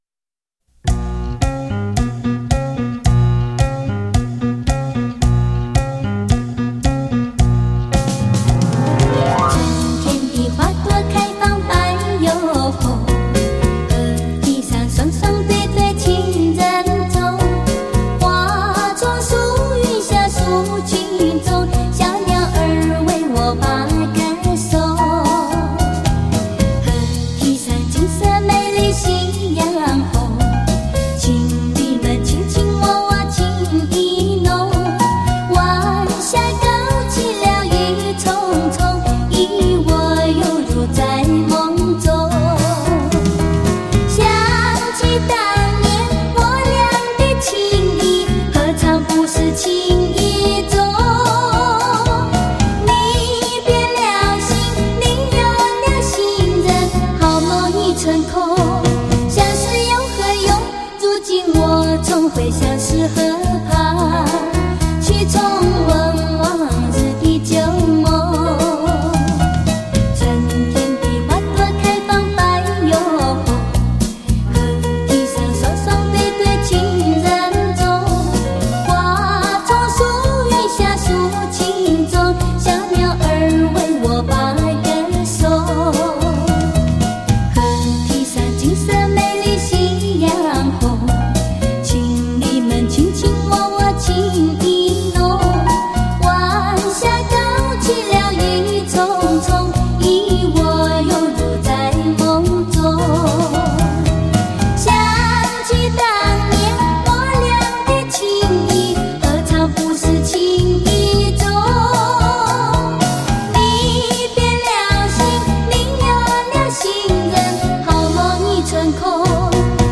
世纪皇牌典藏 巨星原声重炫
那卡西情调金曲 意难忘旧情绵绵